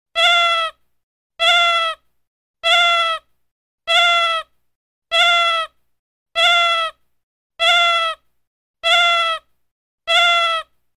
Игрушечная кукла рыдает